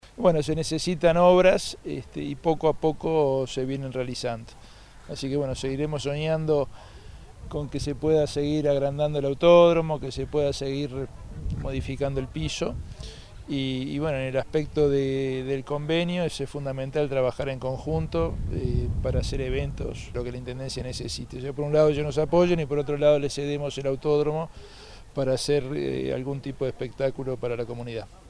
Se realizó la firma de convenio entre la Intendencia de Canelones y la Asociación Uruguaya de Volantes (AUVO) en el Autódromo Víctor Borrat Fabini de El Pinar en Ciudad de la Costa, cuyo objetivo es permitir el desarrollo de las obras de mantenimiento y ampliación del autódromo y las áreas circundantes.